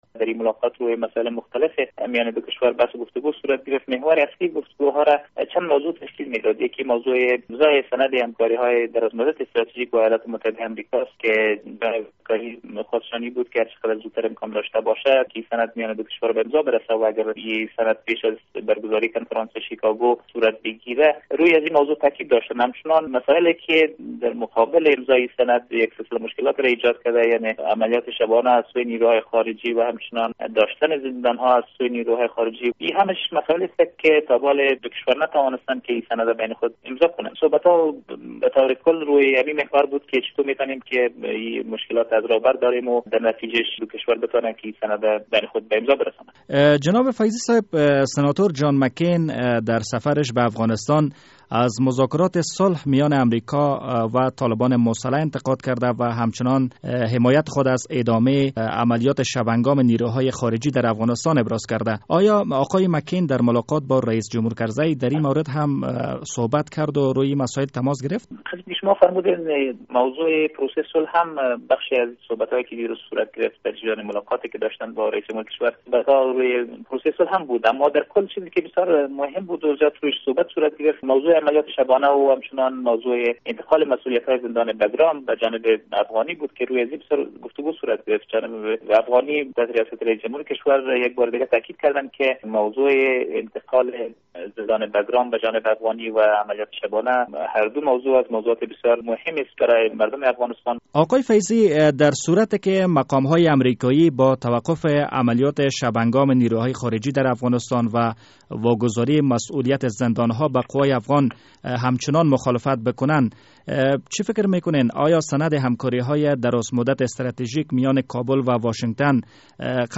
مصاحبه در مورد دیدار هیات سنای امریکا با رییس جمهور کرزی